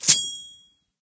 bp_snout_coin_01.ogg